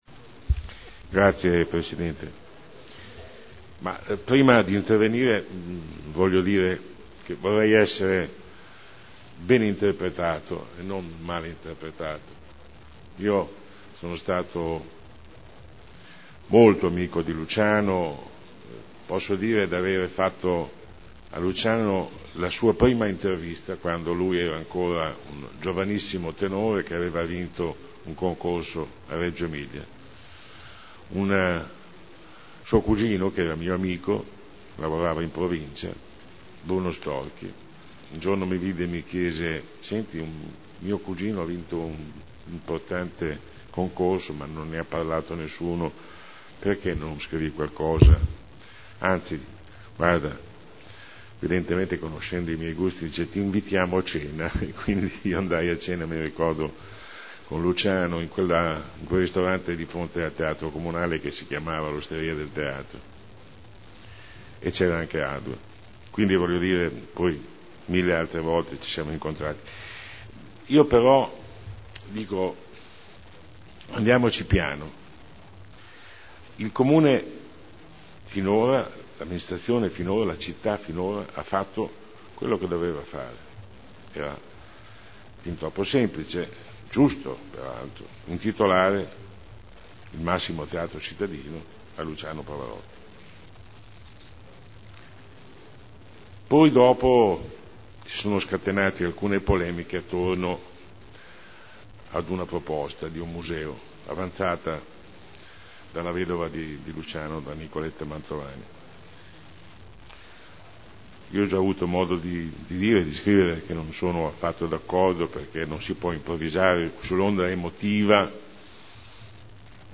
Seduta del 11/11/2010. Dibattito su Ordine del Giorno presentato dal gruppo consiliare Lega Nord avente per oggetto: “Luciano Pavarotti”